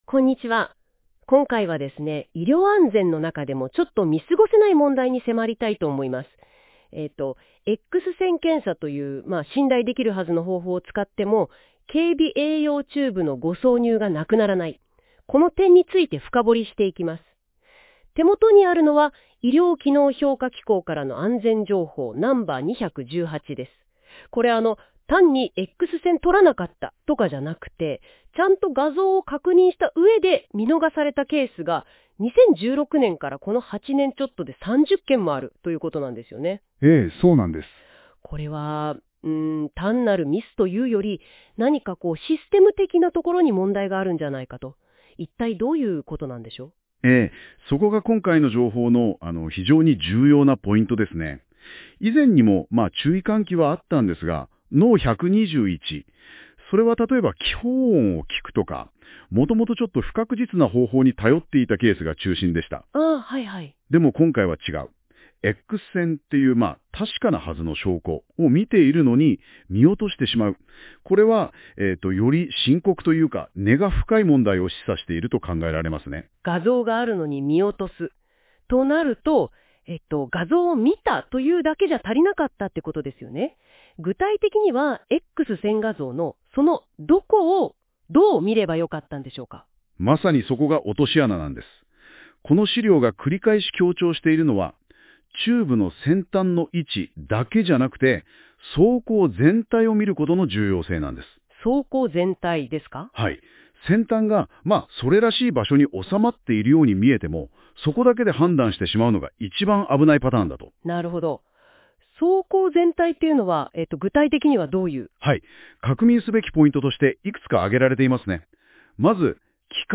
当法人では、医療機能評価機構が発行する「医療安全情報」について、理解を深めていただくための音声解説を配信しております。なお、本ページに掲載している音声は、AIによる自動音声合成で作成しています。そのため、一部に読み方やイントネーションなど不自然に感じられる箇所がありますが、あらかじめご了承ください。